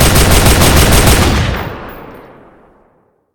gun.ogg